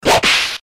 shut-me-up-whip.mp3